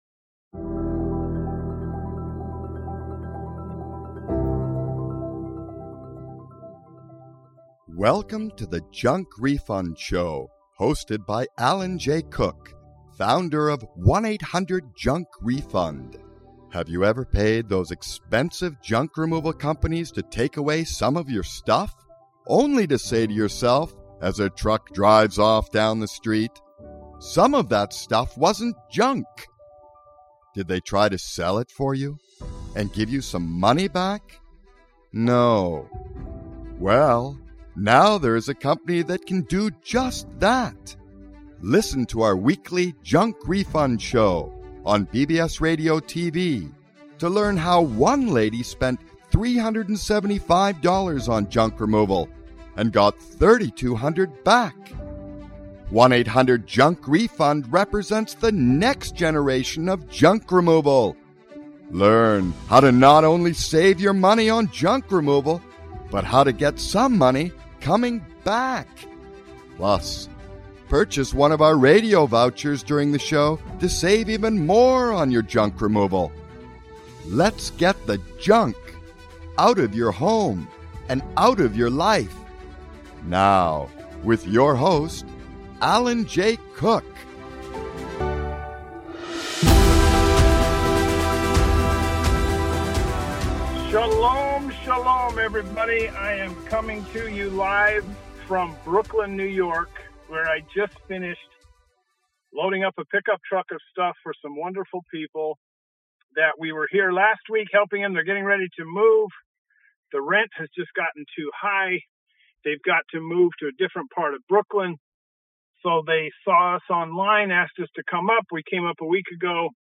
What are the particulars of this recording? Live from Brooklyn, NY...... and on the Job! Then, off the March Madness Basketball Tournament for Alma Mater reunion